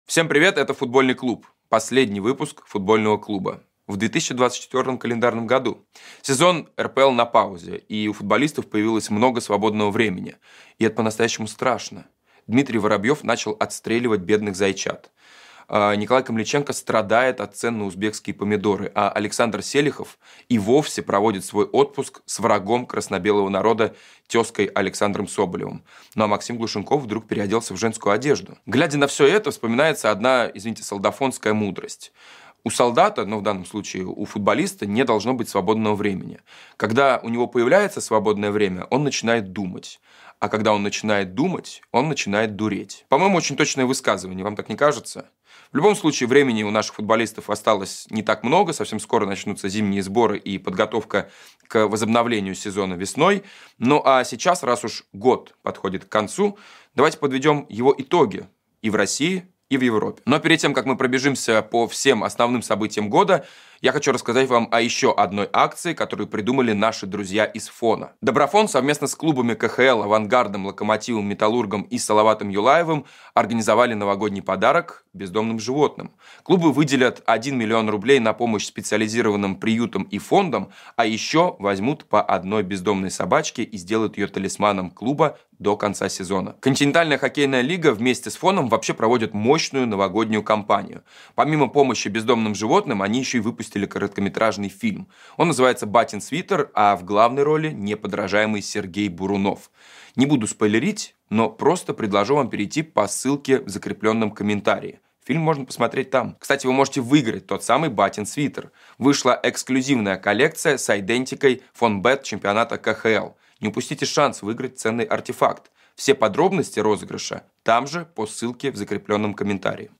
Пеп страдает без Клоппа 24.12.24 Скачать Срочный сбор для “Эха” Подписаться на канал Василия Уткина Последний «Футбольный клуб» в 2024 году. Говорим о главных событиях в российском и зарубежном футболе.